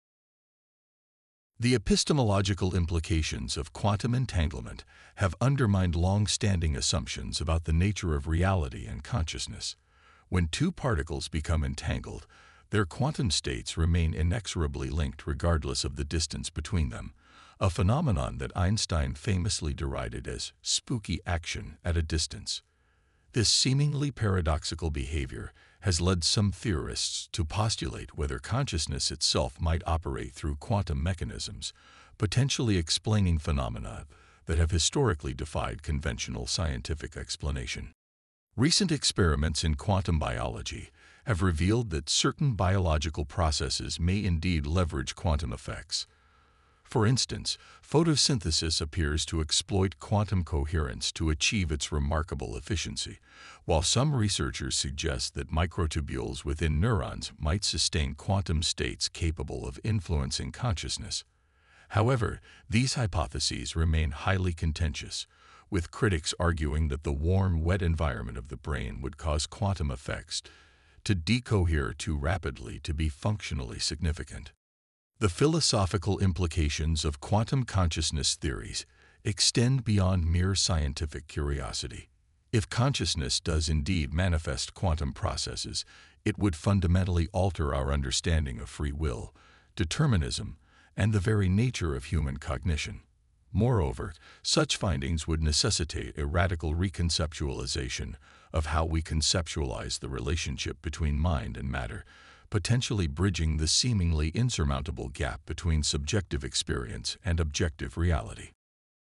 【朗読用音声】A